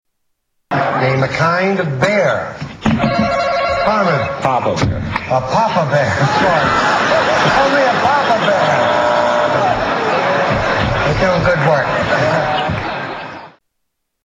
Tags: Media Worst Game show answers in History Television Stupid Answers Game Shows